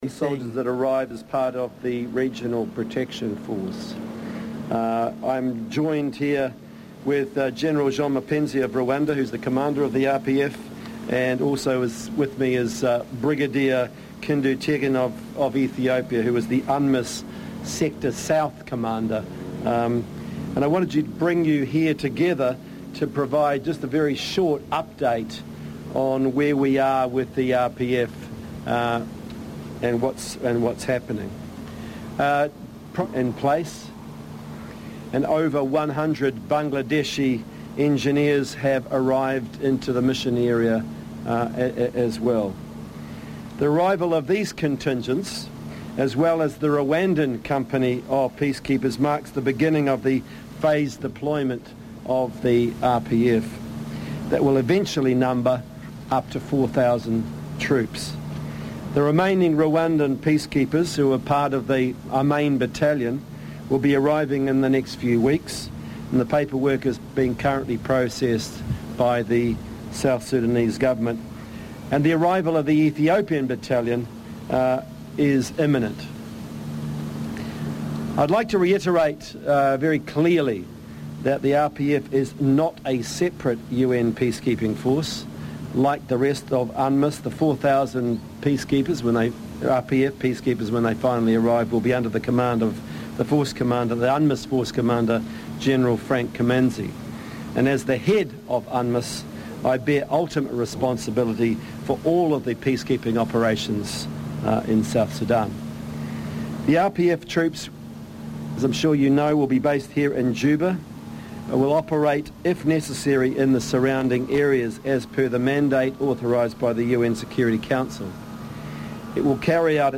David Shearer, who is also the Special Representative of the UN Secretary-General in South Sudan was addressing a news conference in Juba following the arrival, over the weekend, of the first 120 soldiers of the Rwandan battalion of the Regional Protection Force.
Here is David Shearer updating Journalists about the deployment of the Regional Protection Force and its significance.